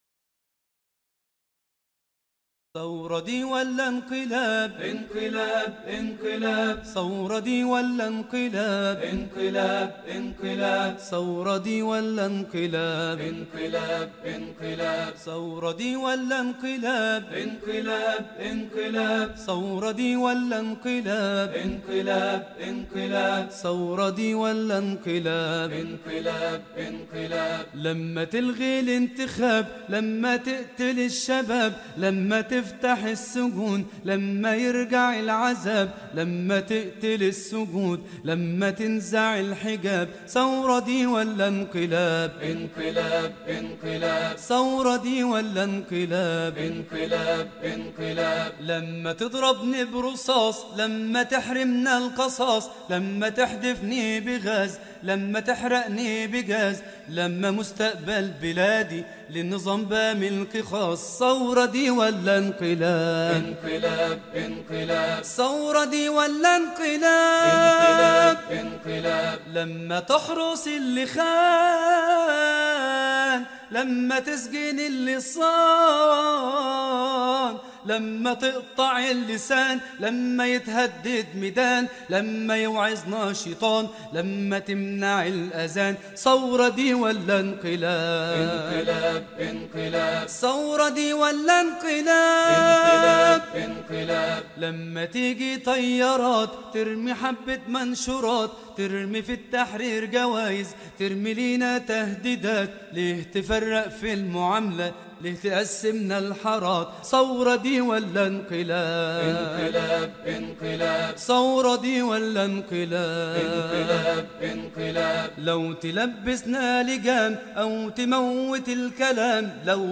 انشودة ثورة دي ولا انقلاب النسخة الاصلية بدون ايقاع